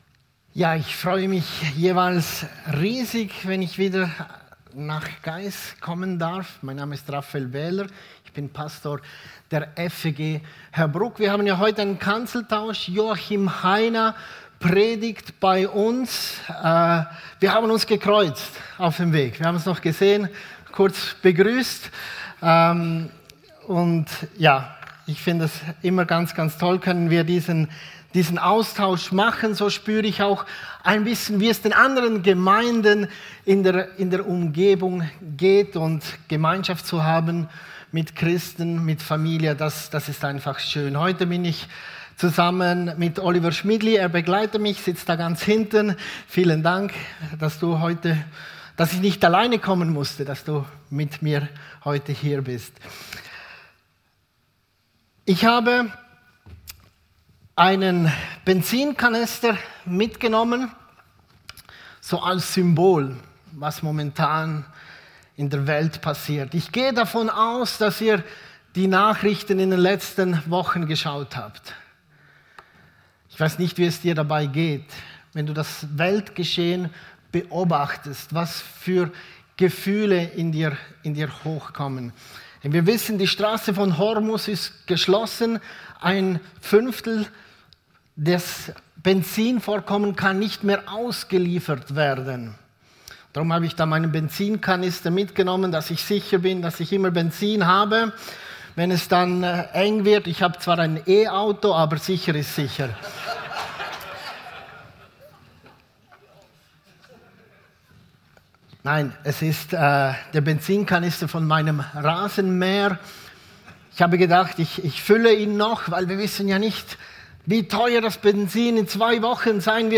Predigt 22.